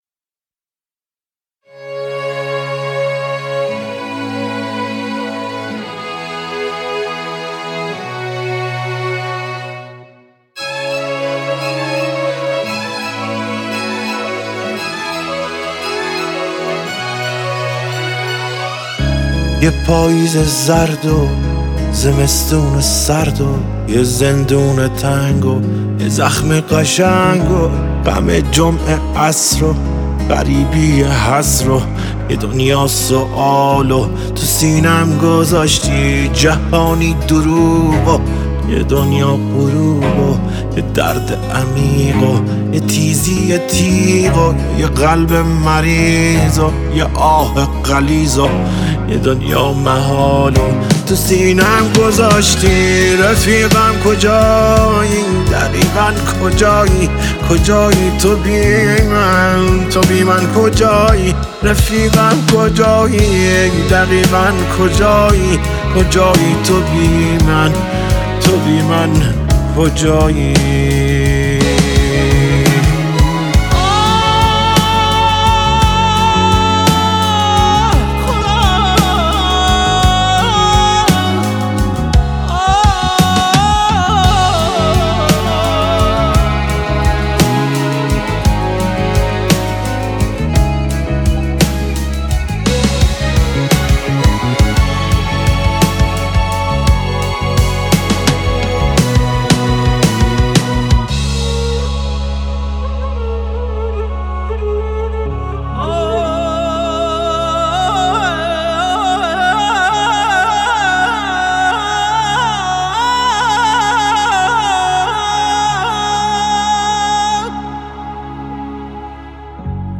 با صدای دلنشین
ملودی‌ای تاثیرگذار